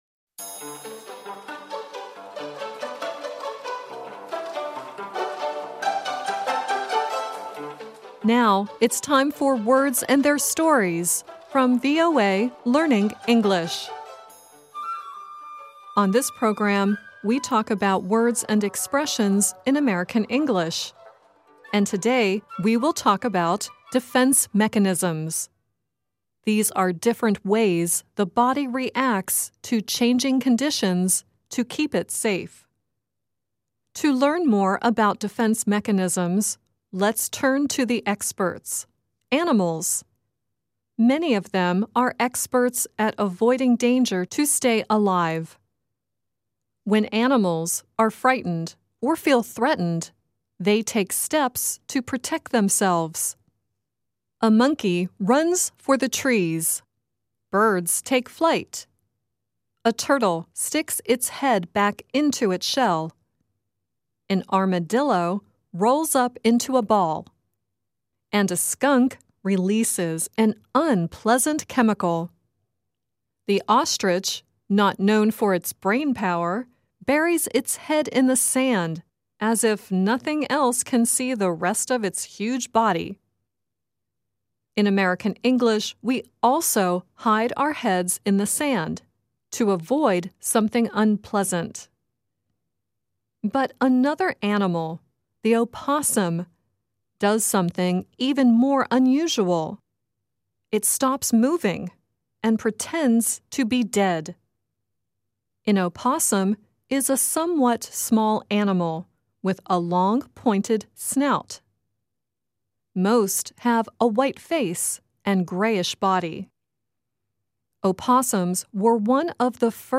The song used in the story is Alan Jackson singing, "Just Playing Possum."